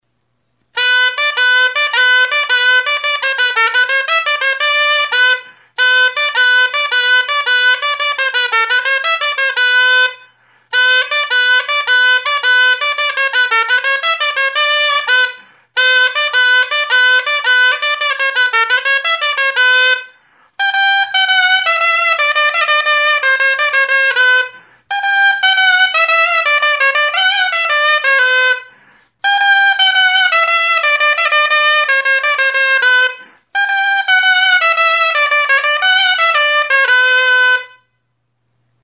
Aerophones -> Reeds -> Double (oboe)
Recorded with this music instrument.
DULTZAINA; DULZAINA
Mihi bikoitzeko soinu-tresna da da.